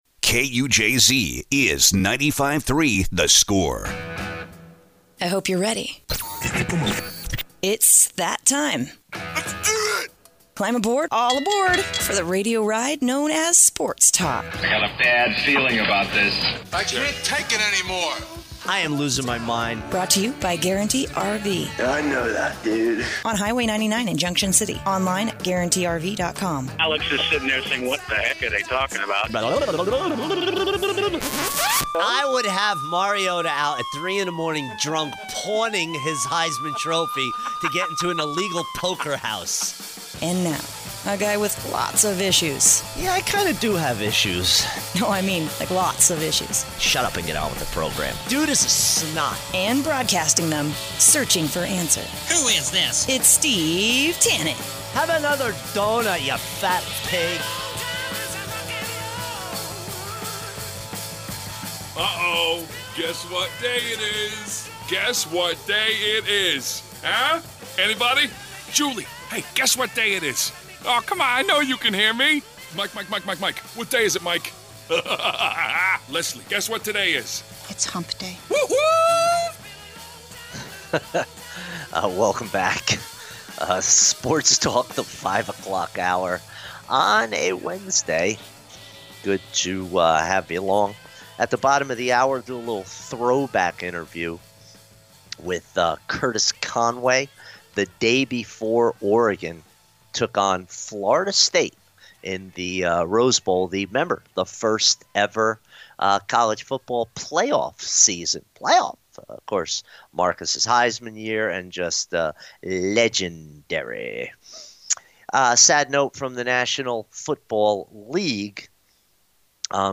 a throwback interview with Curtis Conway previewing Oregon - Florida State Rose Bowl